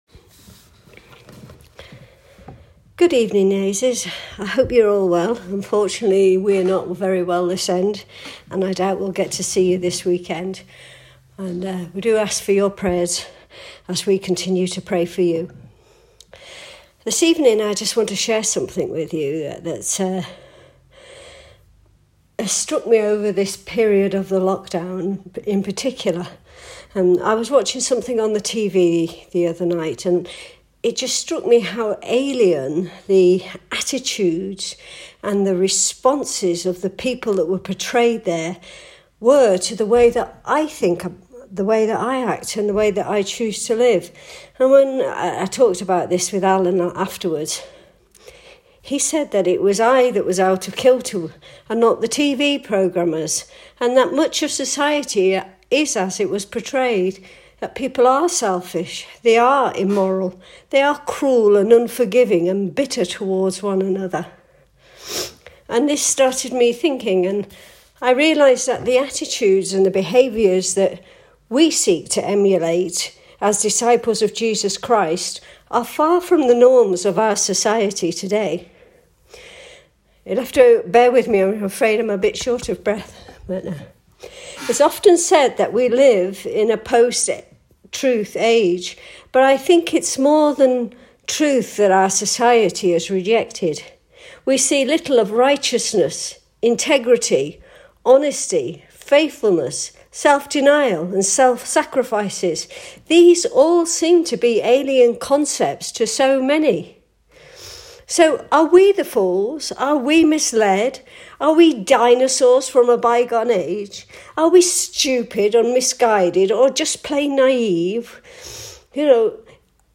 Ladies message